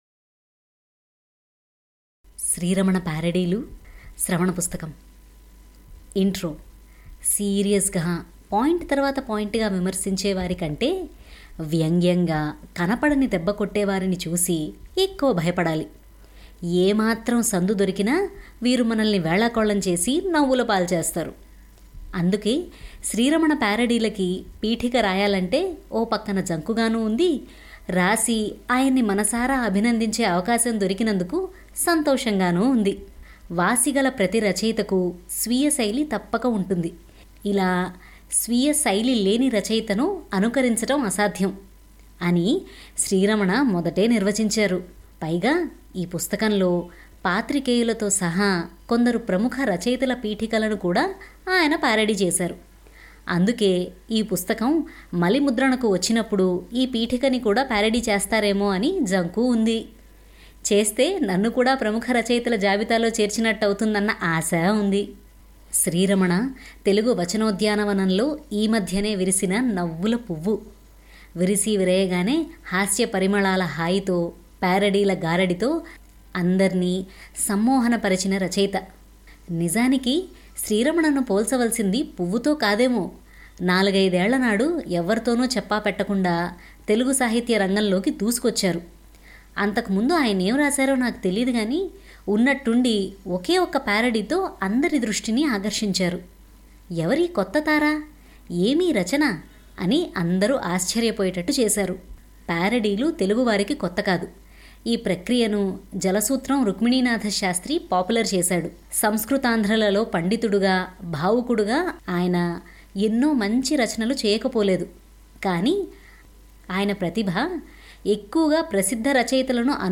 Telugu Audio Books